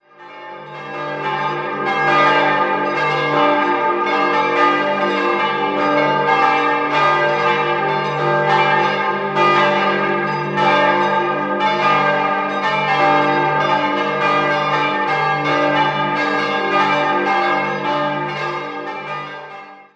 5-stimmiges Geläute: d'-g'-a'-c''-a''